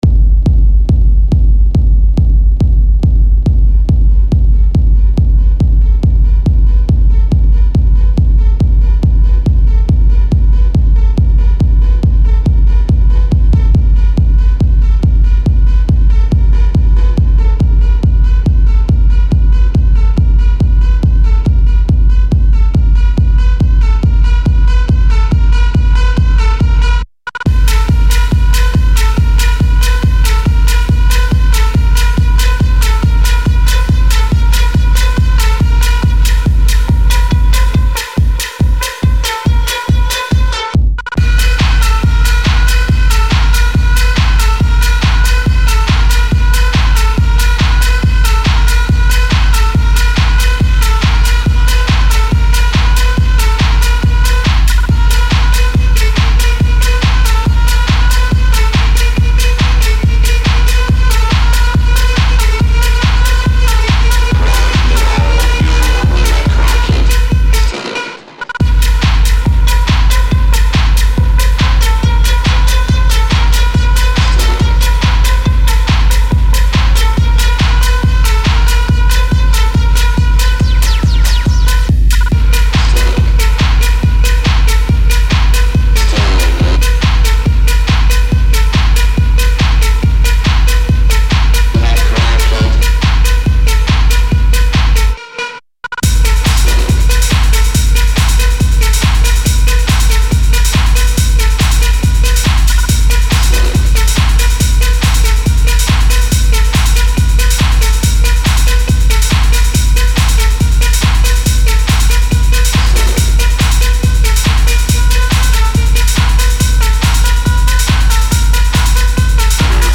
and a club version named extended edit.